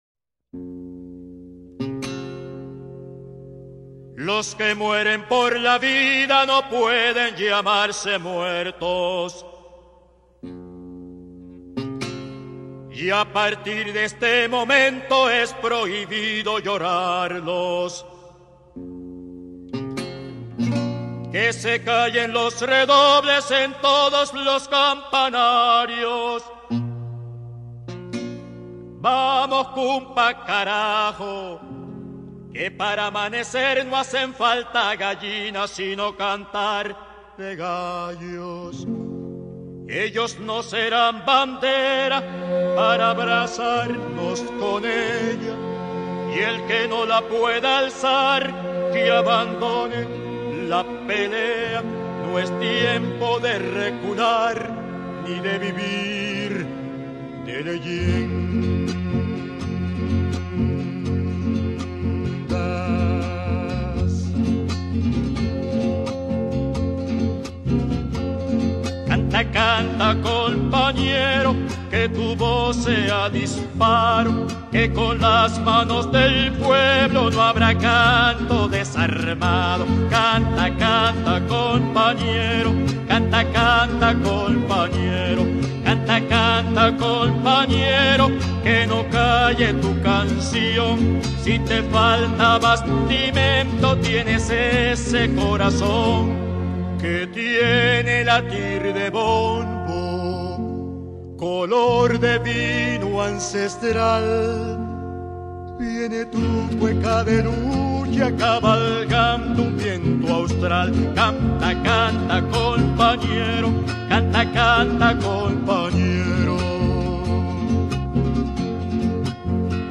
En el acto conmemorativo por el décimo aniversario de la siembra del comandante bolivariano, desde el Cuartel de la Montaña, donde reposan sus restos, el político llamó a todos los venezolanos a ser vigilantes de la paz, del respeto y la tranquilidad de esta patria.